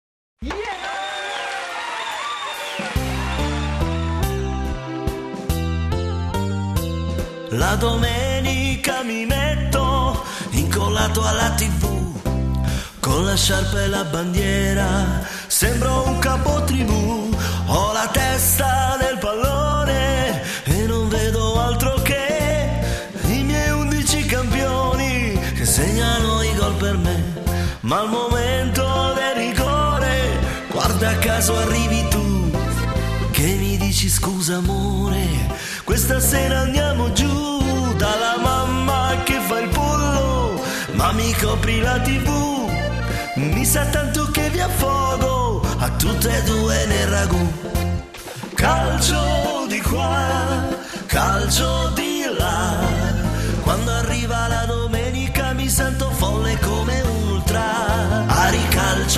Cha cha cha(canzone)
12 brani di liscio e latino